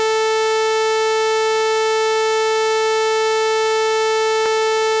Saw